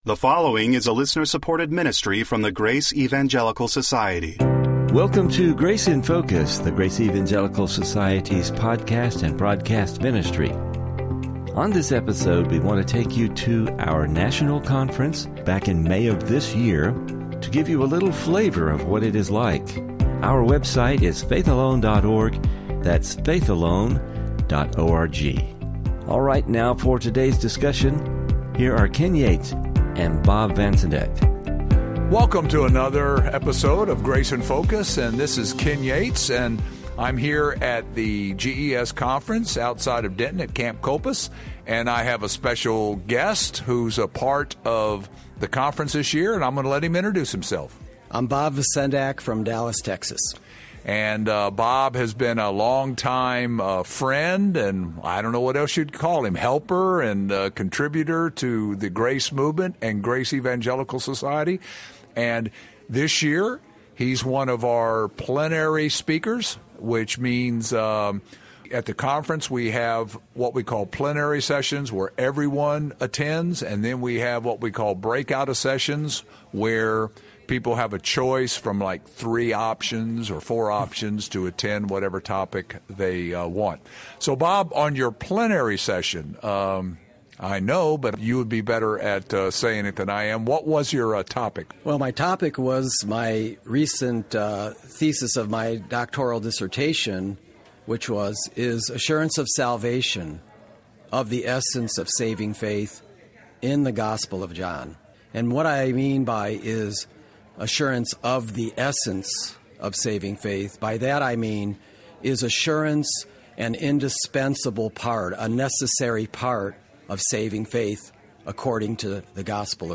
You will hear the “flavor” of our annual national conference as they have a conversation about it.